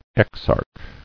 [ex·arch]